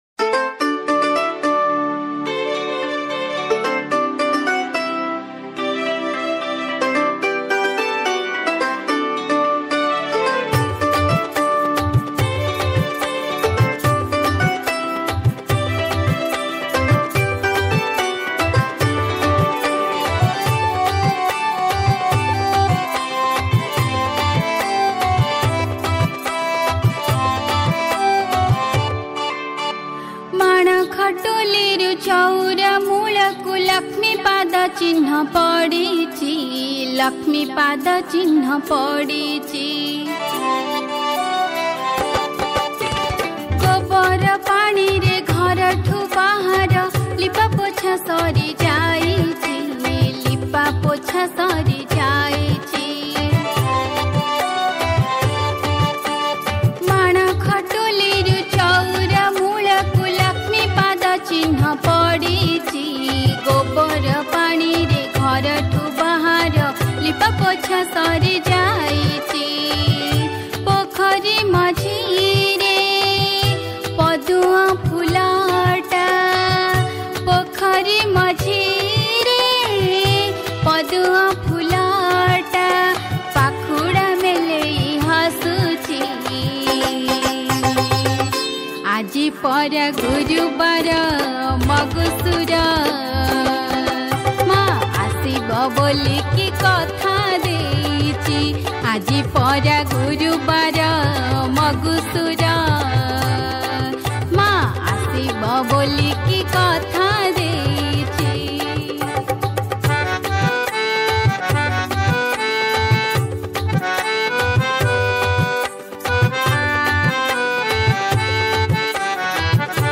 Category : Manabasa Gurubara Bhajan